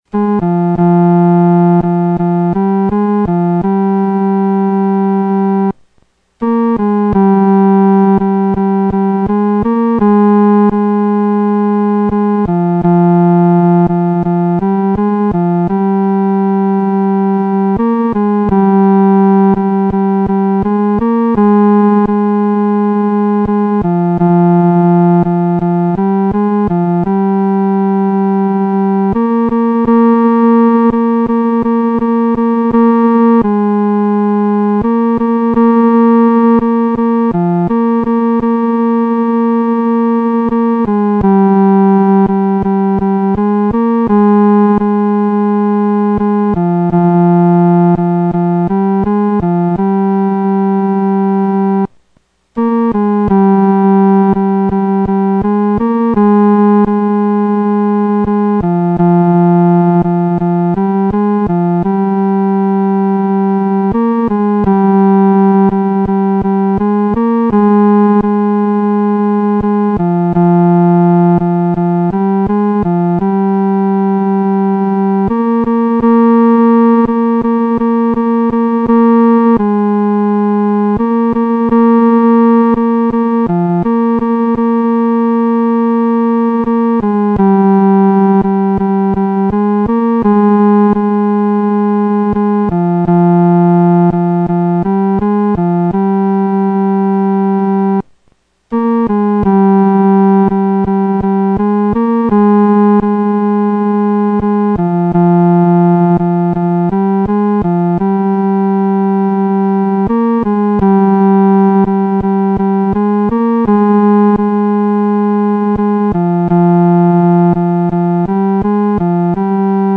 独奏（第三声）